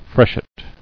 [fresh·et]